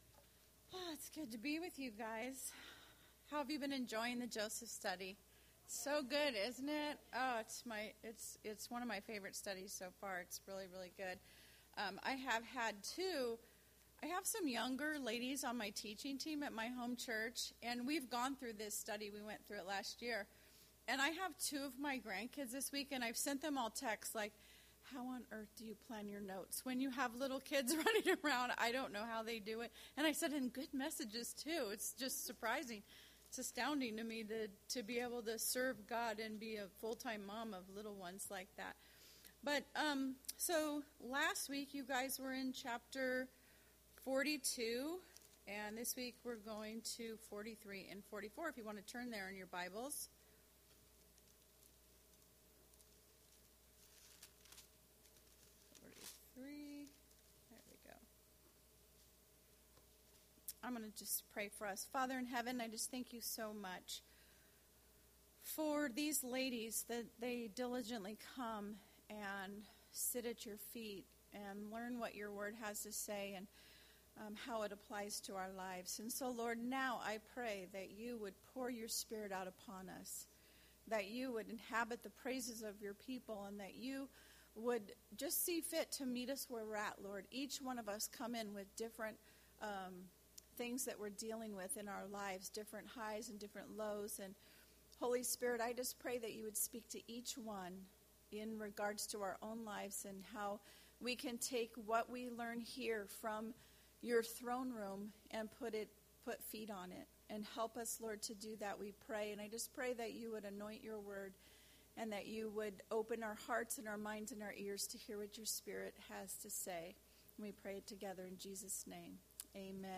Series: Not Your Average Jo Service: Women's Bible Fellowship %todo_render% « World Religion Falls!